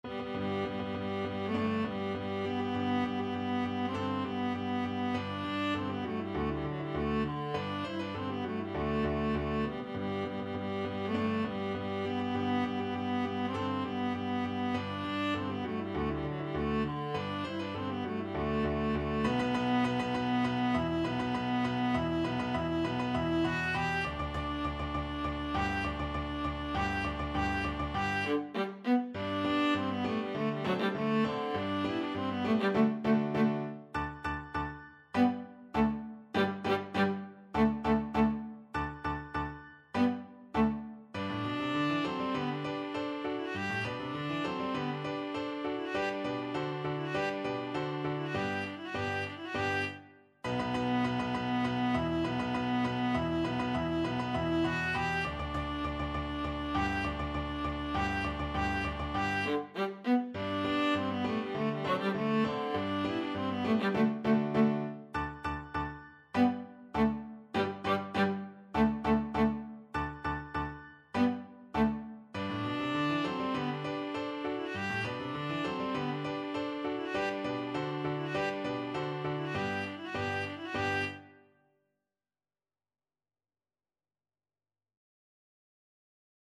Free Sheet music for Viola
G major (Sounding Pitch) (View more G major Music for Viola )
March = c.100
Viola  (View more Intermediate Viola Music)
Classical (View more Classical Viola Music)
yorckscher_marsch_VLA.mp3